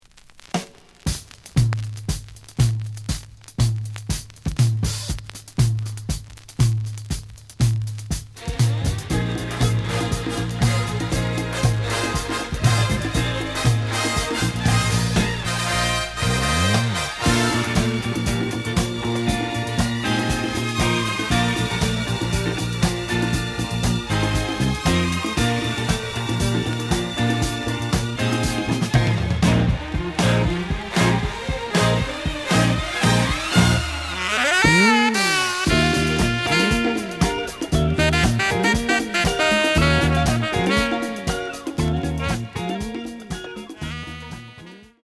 The audio sample is recorded from the actual item.
●Genre: Jazz Funk / Soul Jazz
Looks good, but slight noise on both sides.)